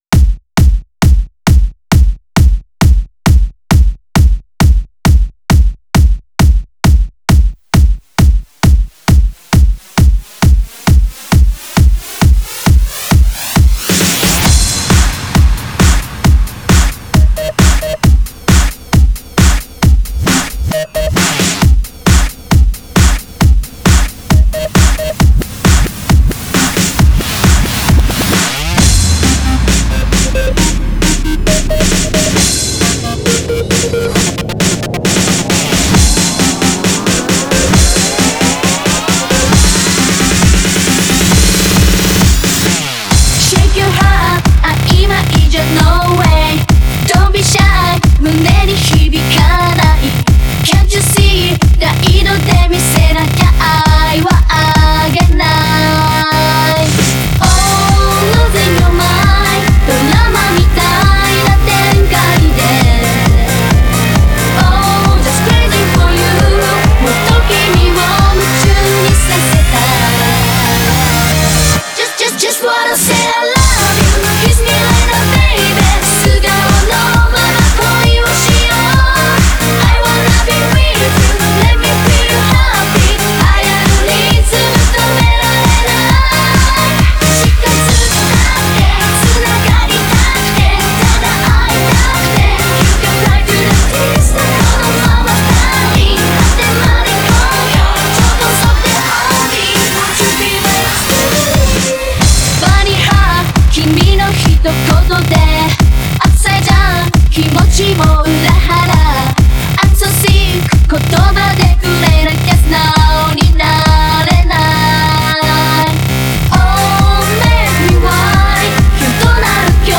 Genre(s): Electro-House